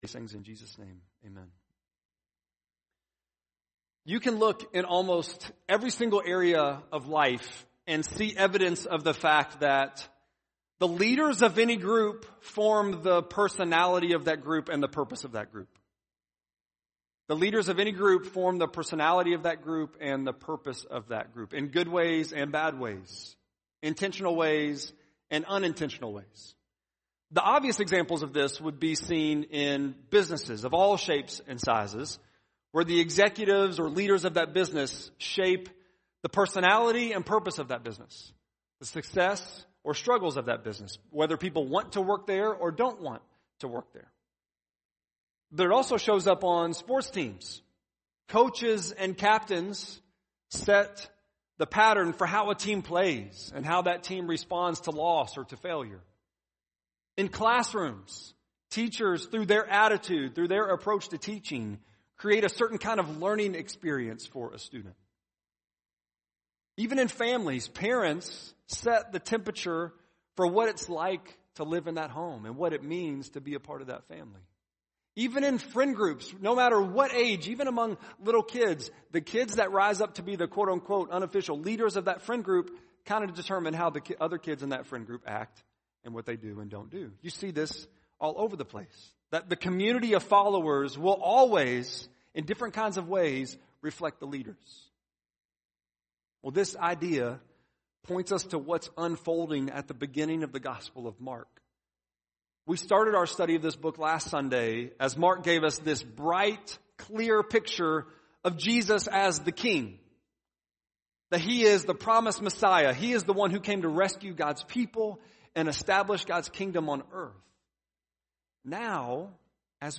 3.13-sermon.mp3